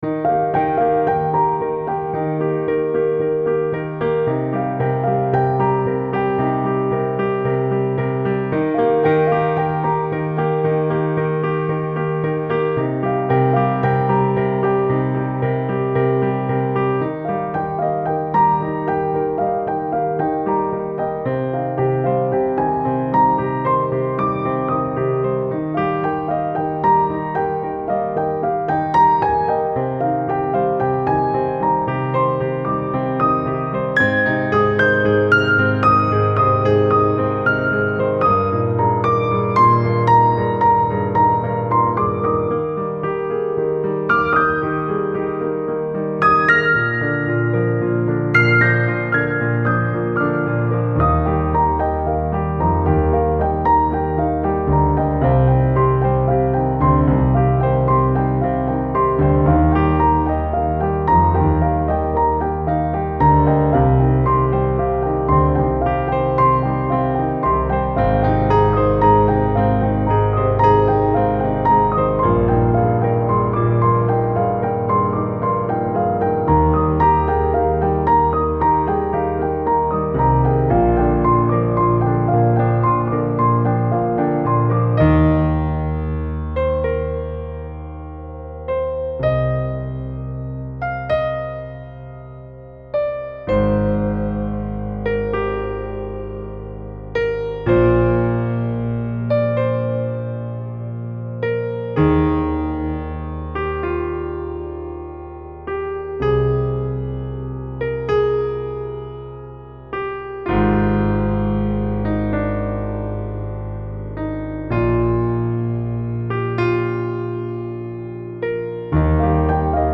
Beautiful and uplifting piano duet.
Style Style Corporate, Soundtrack
Mood Mood Bright, Calming, Uplifting
Featured Featured Piano
BPM BPM 113